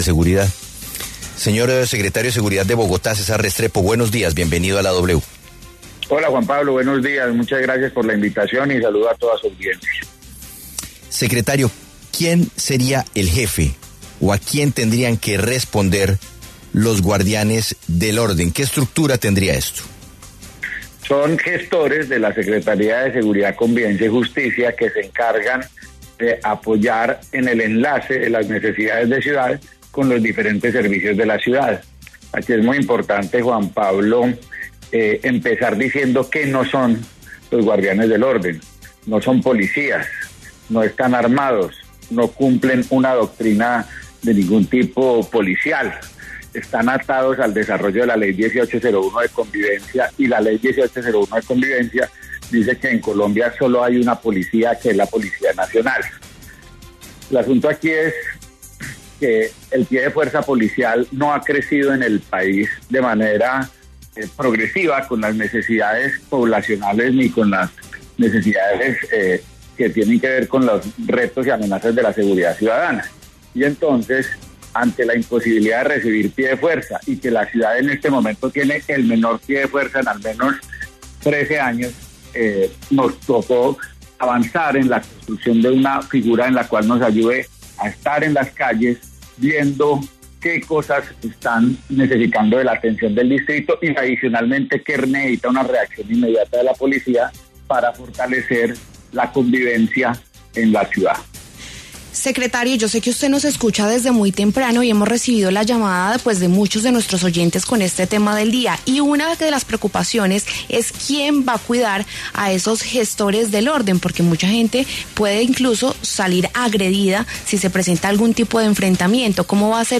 El secretario de Seguridad, César Restrepo, explicó las funciones de los ‘Guardianes del Orden, anunciados por Galán, dijo que no estarán armados ni tendrán funciones policiales.